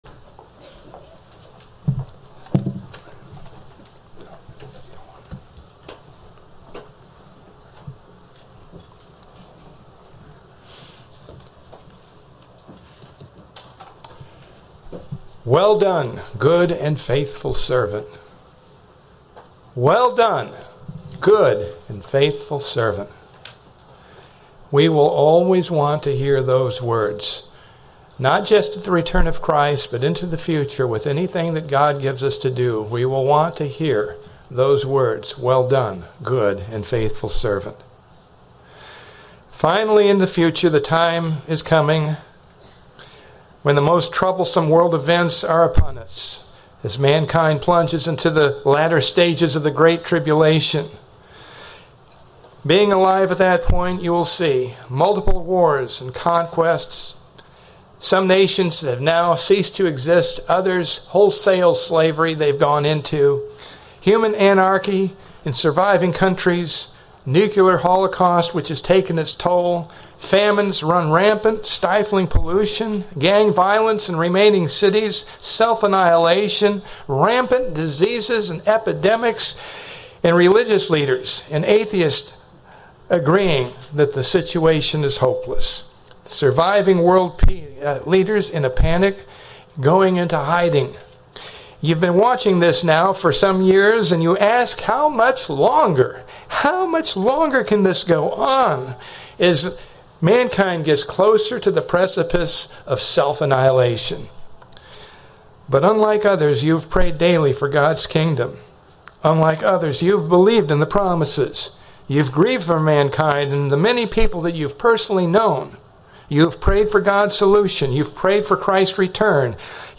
Sermons
Given in Ft. Myers, FL